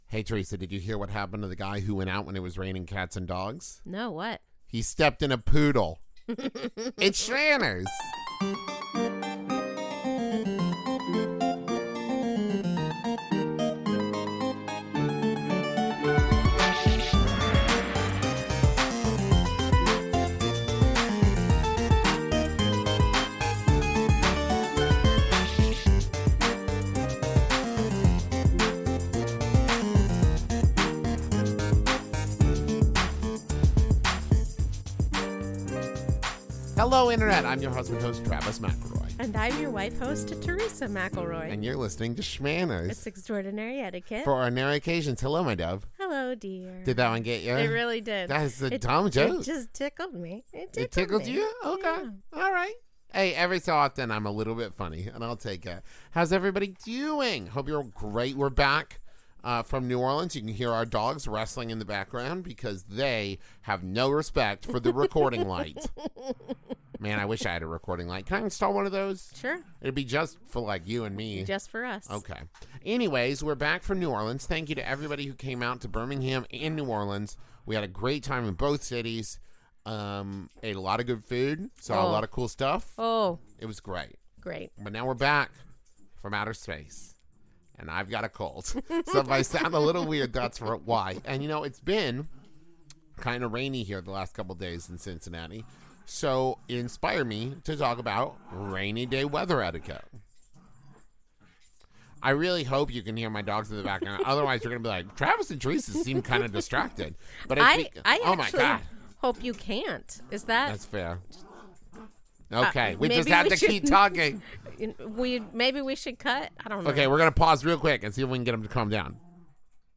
You can hear our dogs wrestling in the background because they have no 1:04.0 respect for the recording light.